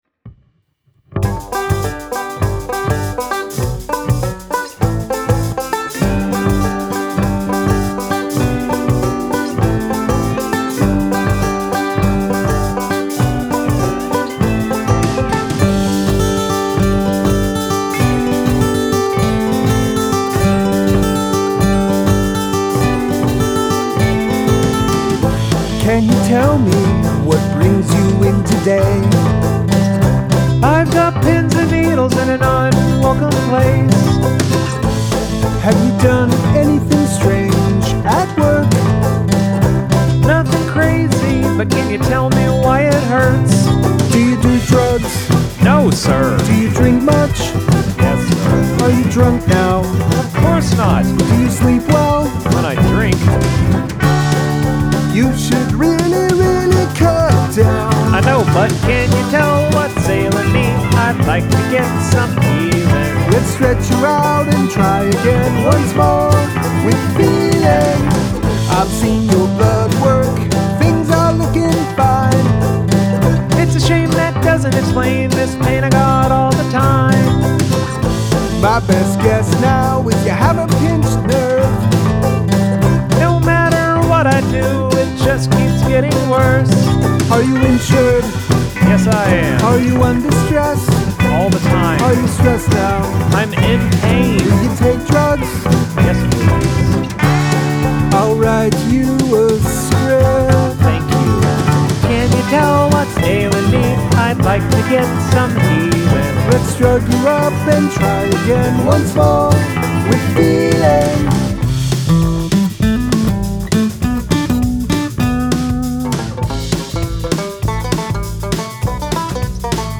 banjo
The vocals seem maybe a bit more muddy than usual.
The almost stilted rhythm is interesting.